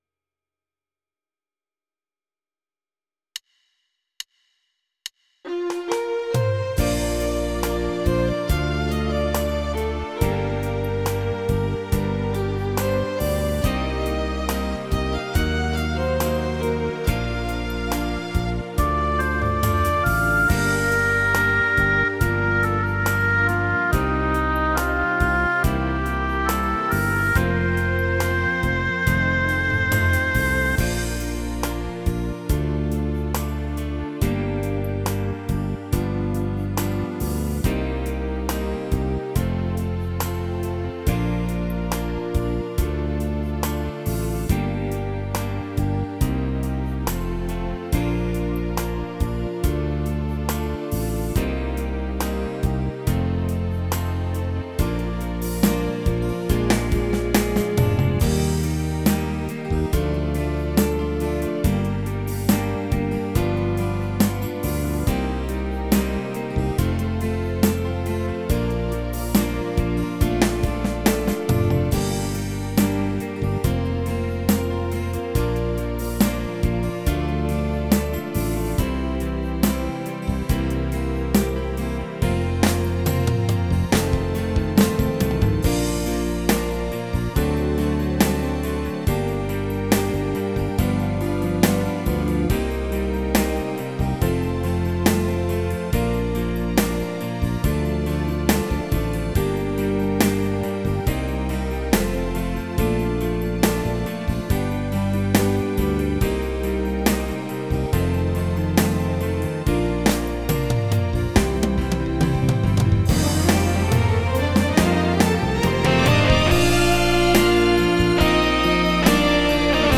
Tone Nữ (Bb) / Tốp (D)
•   Beat  04.